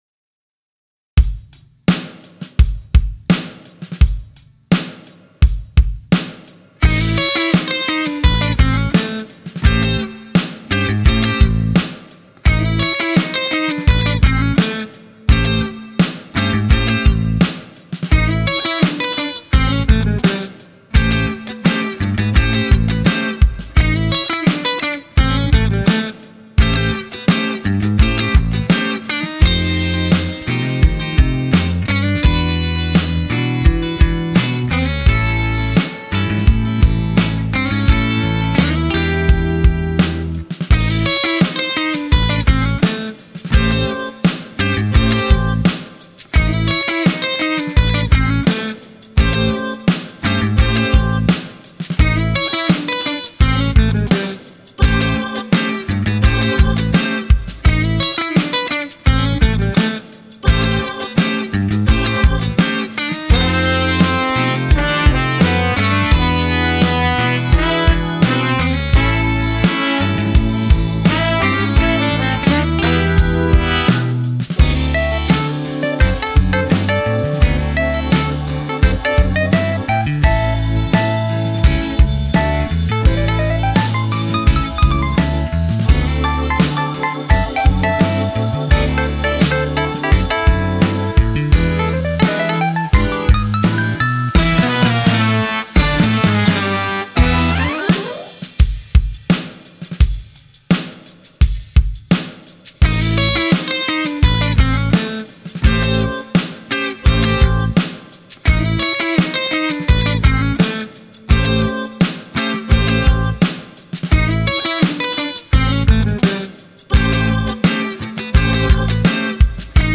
But the tune soon took on a bit of a “Pop” flavor as things progressed.
The guitars used included my Blackguard Tele and my Les Paul.
I created the drum track in Logic Pro X and the horn track using my Juno DS-6.